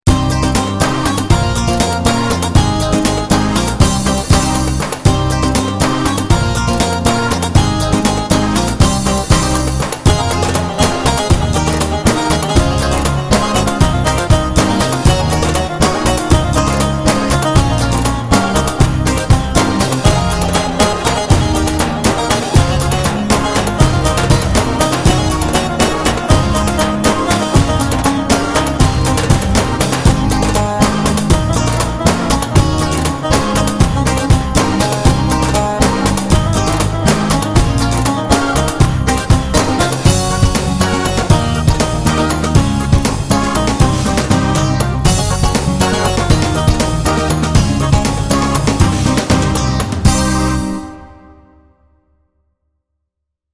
!!!Roland Bk5or Karadeniz 5-16 demo!!!
Roland Bk5or özel sitesine gelen isteklerinden 5-16 karadeniz ritim demosudur.yorumlarınız önemlidir.iyi dinlemler...